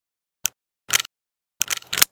pm_reload.ogg